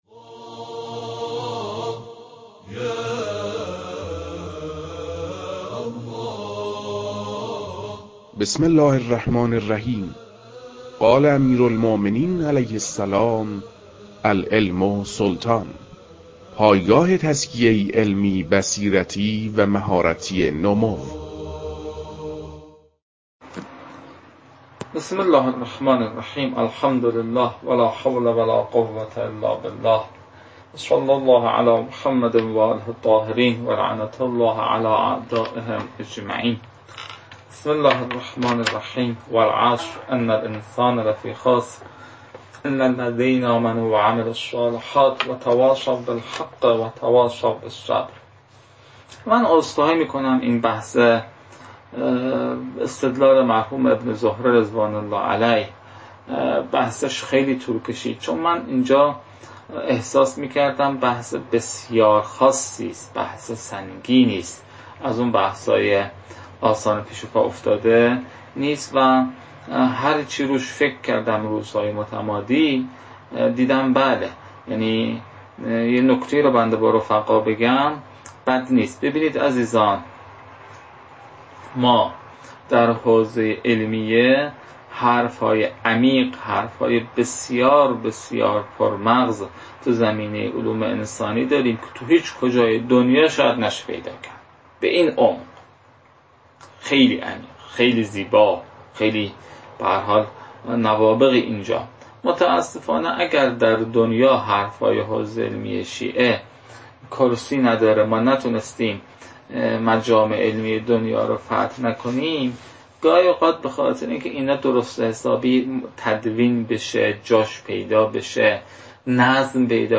در پایان درس، تکالیف پایین توسط استاد ارائه گشته‌است: